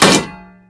katana_hitwall1.wav